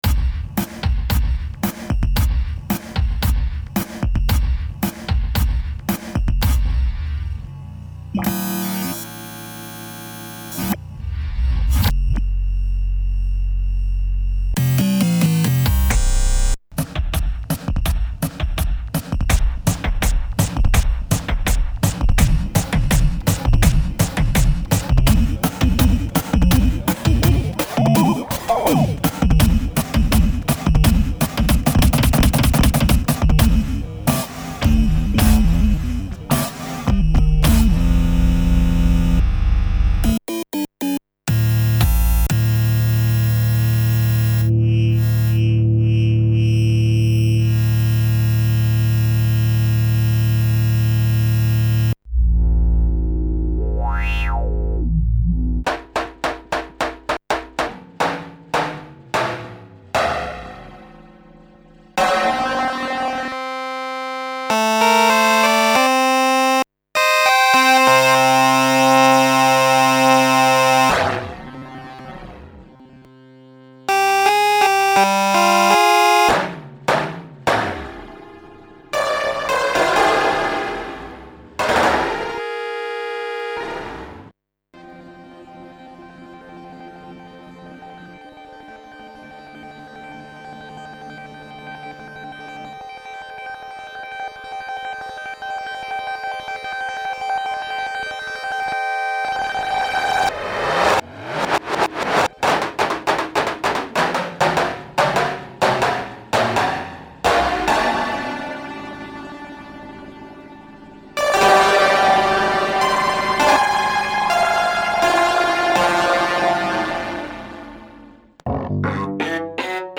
VSynth GT Digital Synthesizer + modulation step sequencer
Wave Shapes samples digital saw triangle random noise pulse supersaw
SOUND outer space clicks and cuts, sample mangling and beat destruction of all kind, the vsynth is a very cool performance machine since it has d-beams (control by hand movement) and a 2D-pad to stop and show +"draw" the sample movements. nice performance step sequencer! interesting hi-end vocoder VC2 (optional).
V-Synth GT Demo
vsynth_gt_demo.mp3